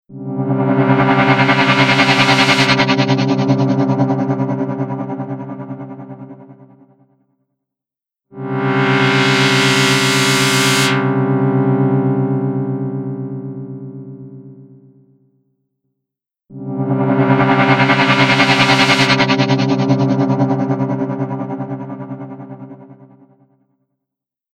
Tube Amp-style Spring Reverb
Spring | Synth | Preset: The Razor’s Edge
Spring-Eventide-Synth-Pad-The-Razors-Edge.mp3